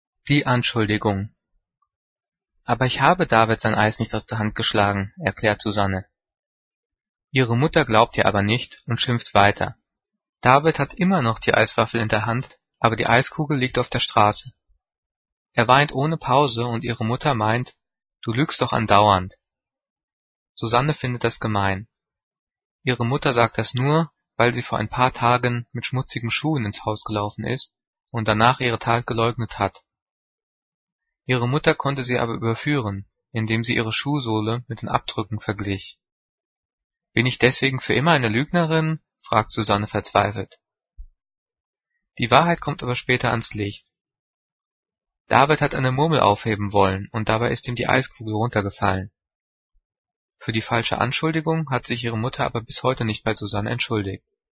Gelesen:
gelesen-die-anschuldigung.mp3